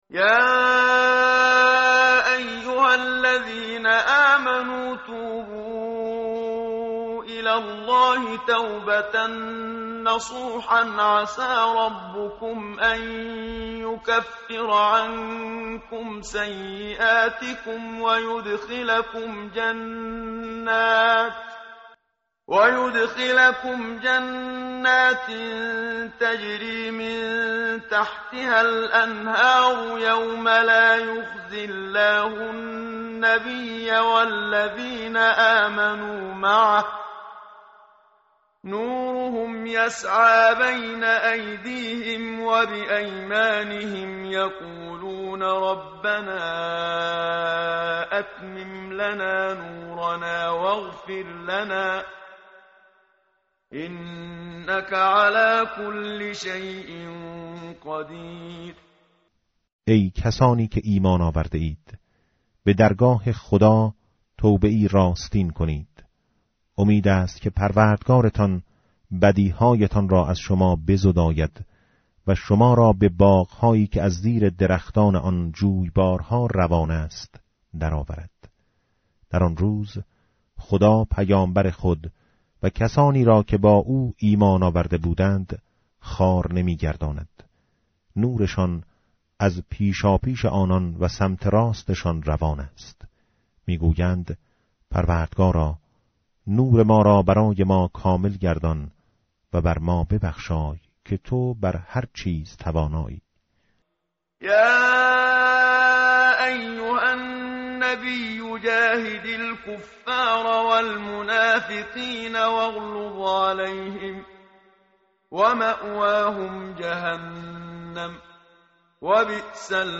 متن قرآن همراه باتلاوت قرآن و ترجمه
tartil_menshavi va tarjome_Page_561.mp3